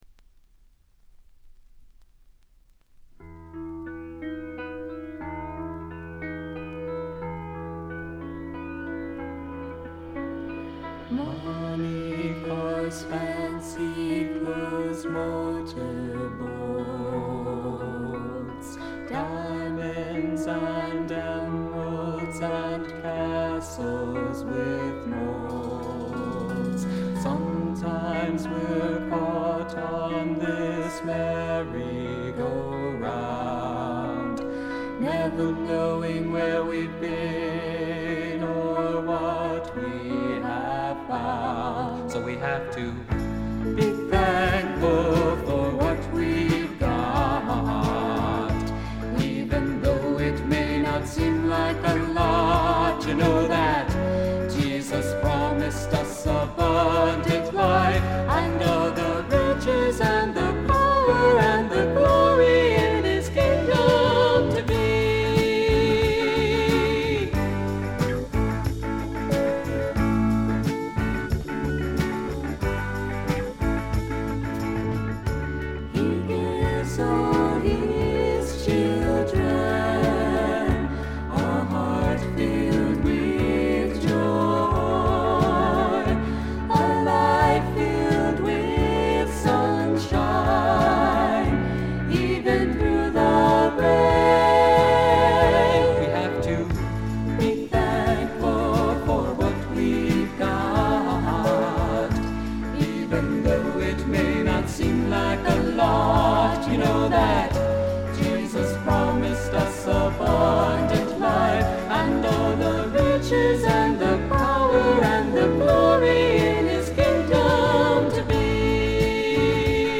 ほとんどノイズ感無し。
全体に涼やかでメロウな味わいがたいへんに美味なもので、この音で好事家達が見逃すわけがありませんね。
試聴曲は現品からの取り込み音源です。